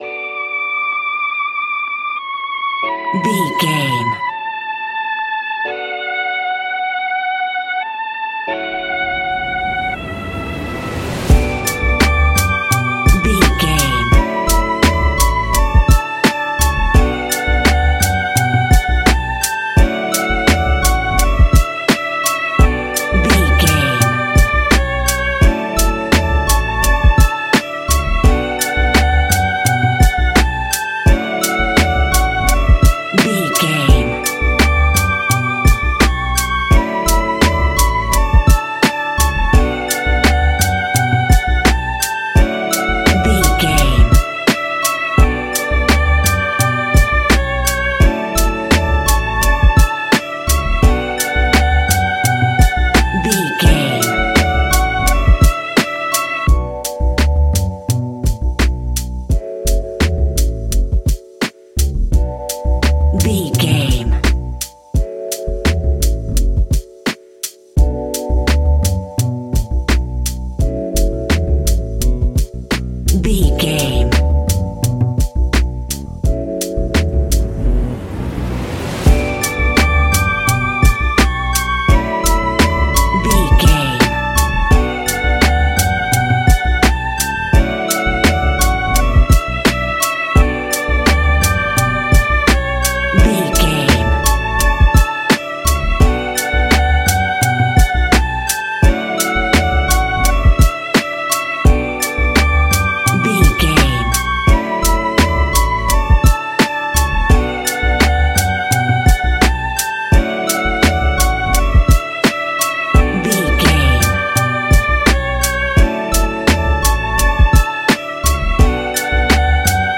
Ionian/Major
B♭
laid back
Lounge
sparse
new age
chilled electronica
ambient
atmospheric
morphing